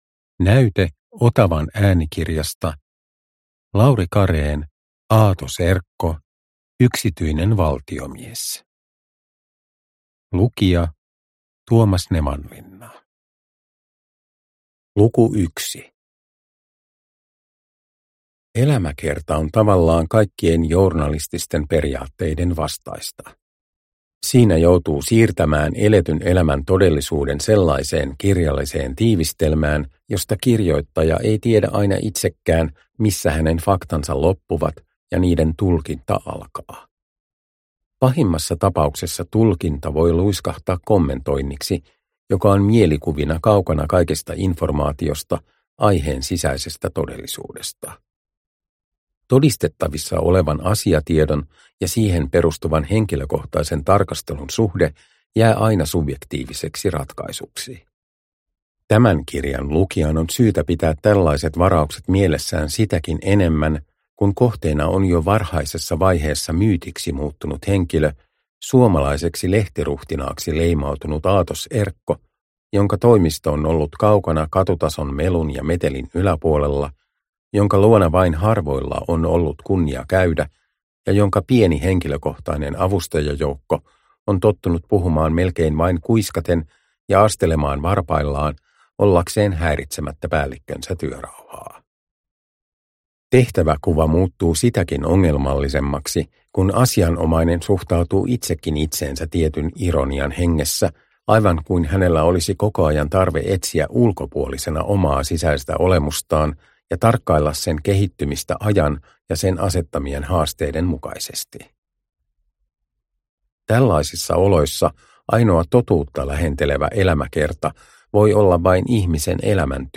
Aatos Erkko – Ljudbok – Laddas ner
Uppläsare: Tuomas Nevanlinna